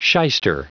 Prononciation du mot shyster en anglais (fichier audio)
Prononciation du mot : shyster